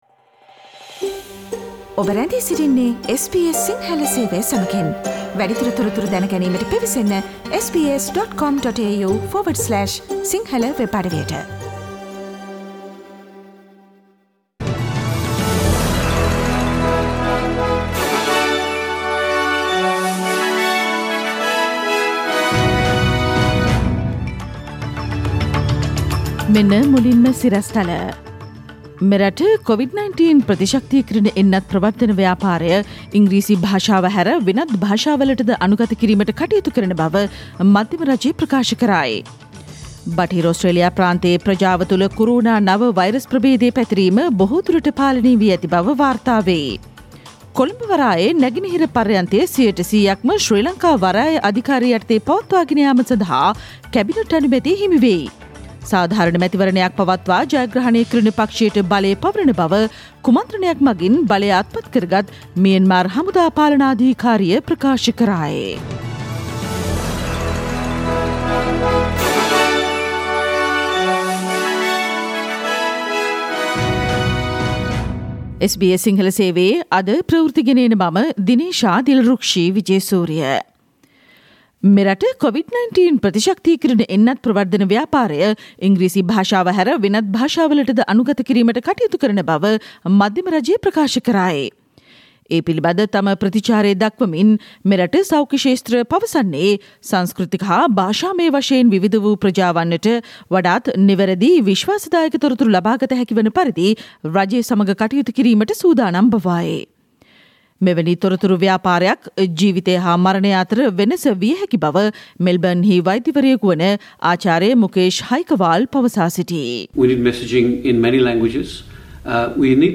Myanmar's military junta says it will hand over the power after a fair election: SBS Sinhala radio news on 2 February 2021
SBS Sinhala radio news on 2 February 2021.